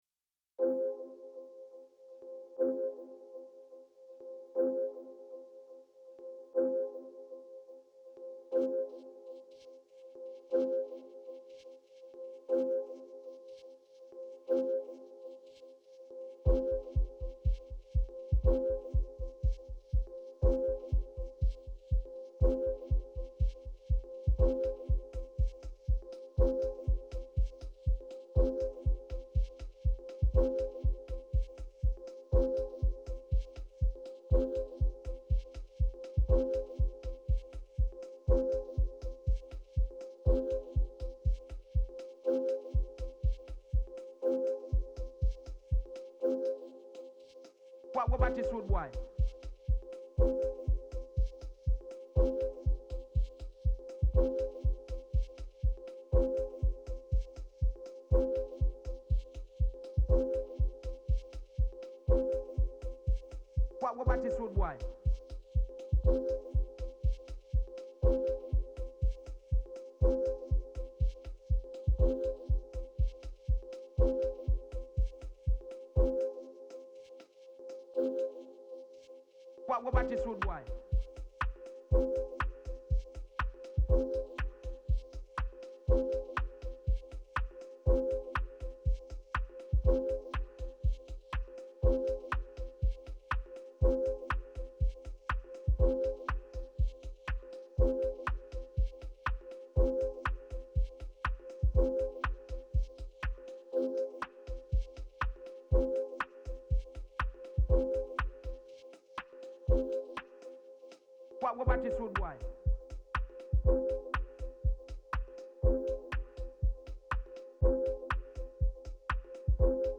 digi “dub”. not too happy with the sound selection afterwards, but it is what it is.